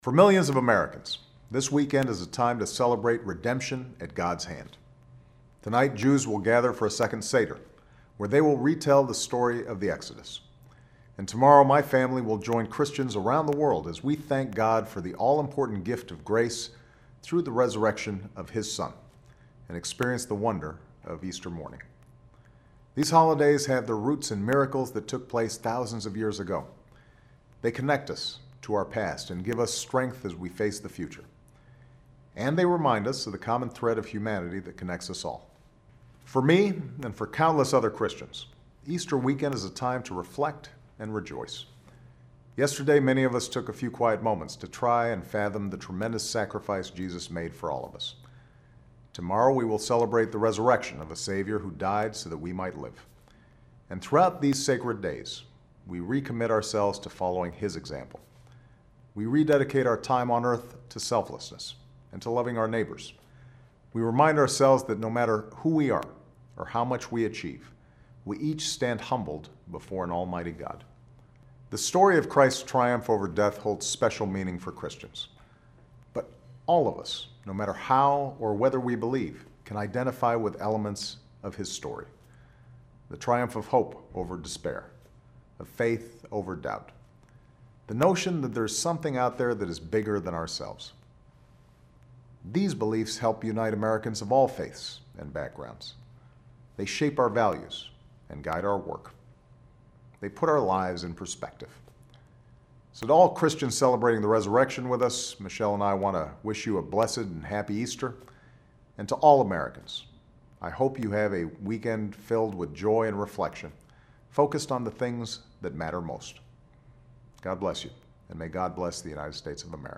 Remarks of President Barack Obama
Weekly Address